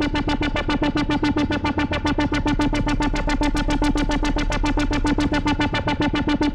Index of /musicradar/dystopian-drone-samples/Tempo Loops/110bpm
DD_TempoDroneA_110-D.wav